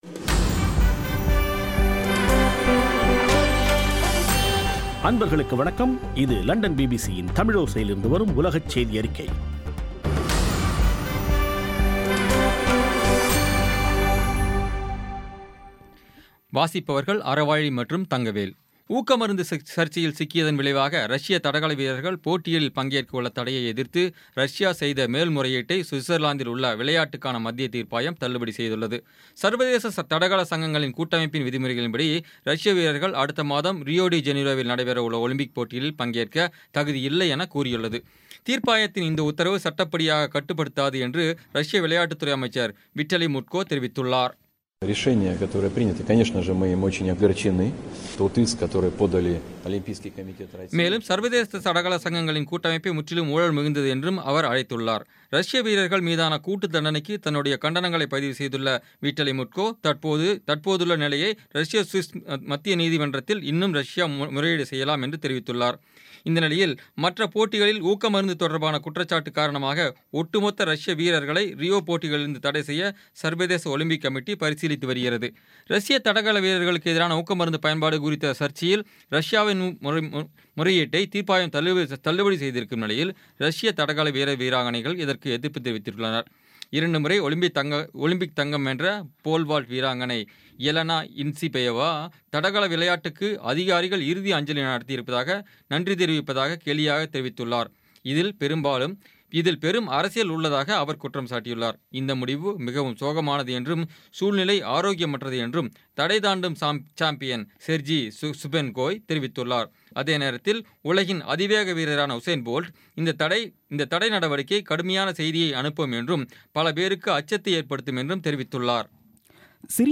இன்றைய (ஜூலை 21ம் தேதி ) பிபிசி தமிழோசை செய்தியறிக்கை